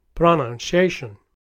Many non-natives begin the word with a strong stress, saying PRO-noun-ciA-tion, something like: